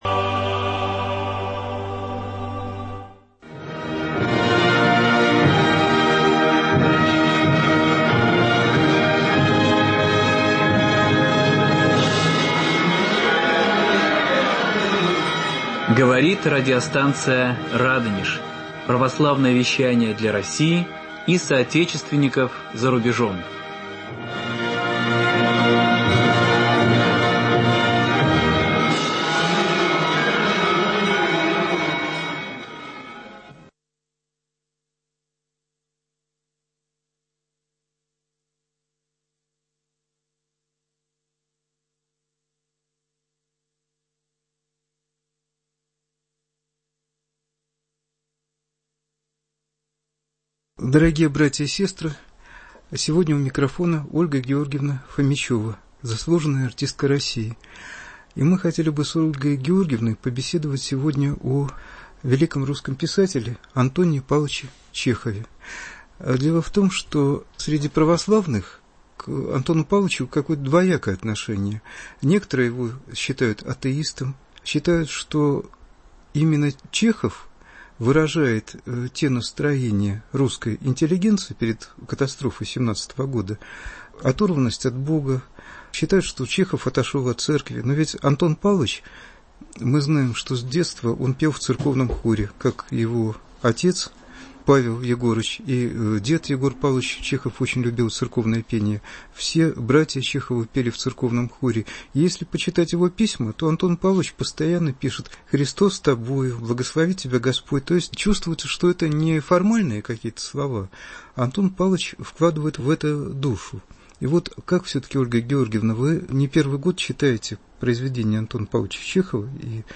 литературно-музыкальная композиция, посвященная А. П. Чехову.